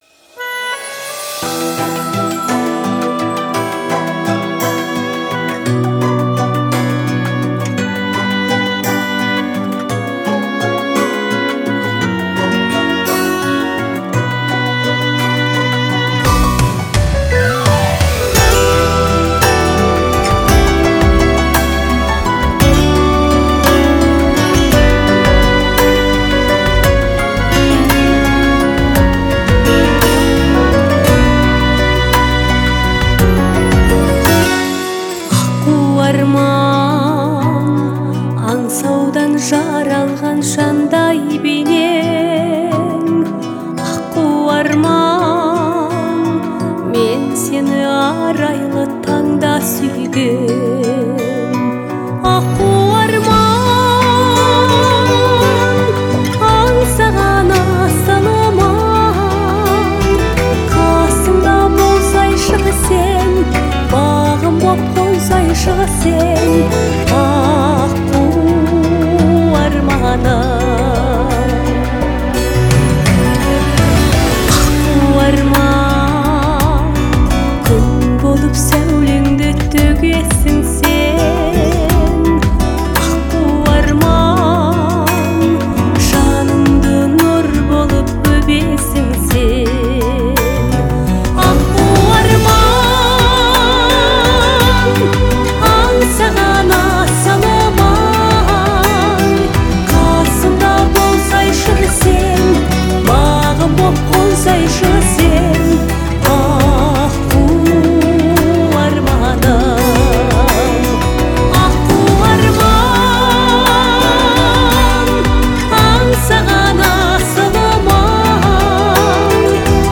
выразительным вокалом и искренностью